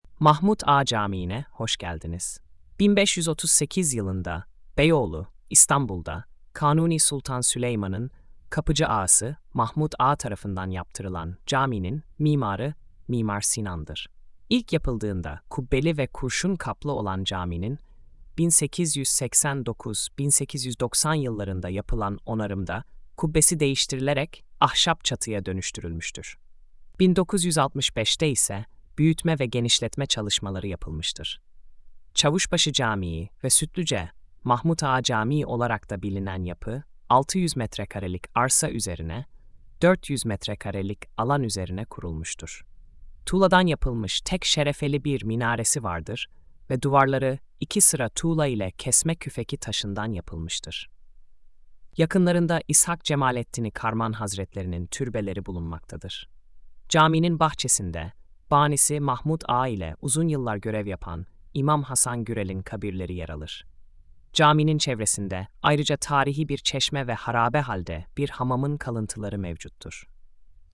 Sesli Anlatım: